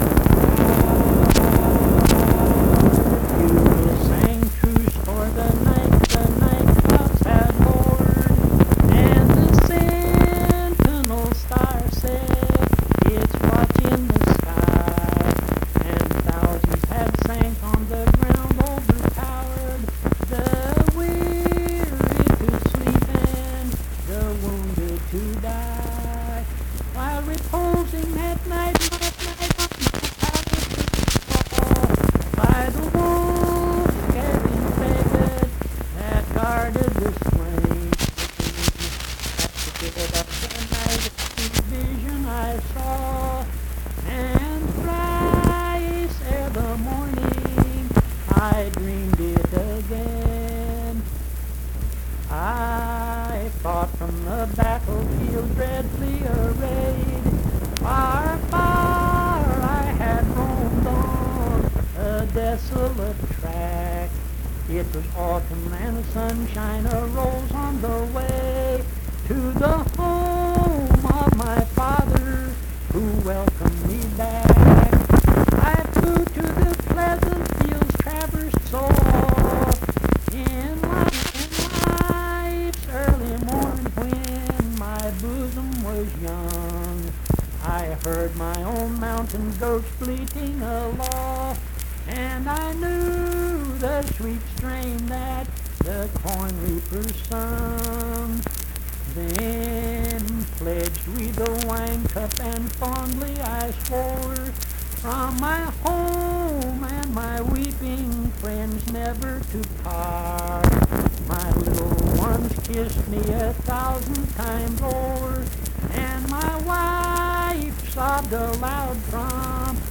Accompanied (guitar) and unaccompanied vocal music
Verse-refrain 3d(8). Performed in Mount Harmony, Marion County, WV.
Voice (sung)